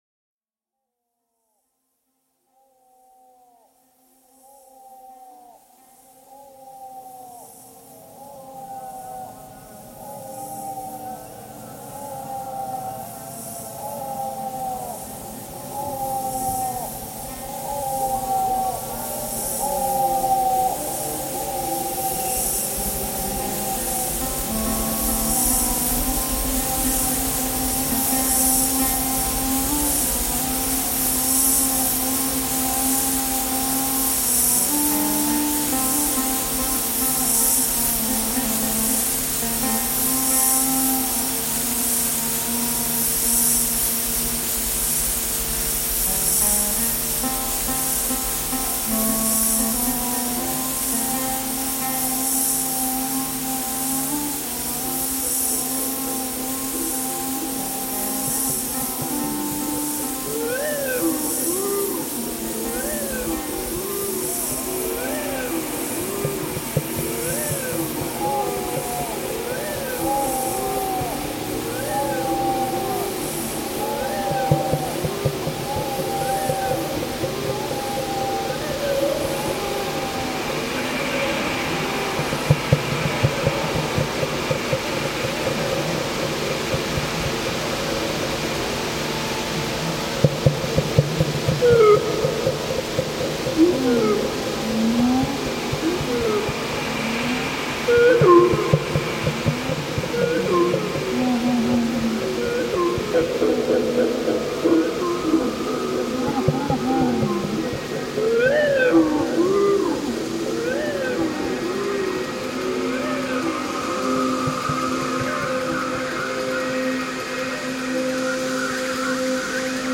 Mount of Olives recording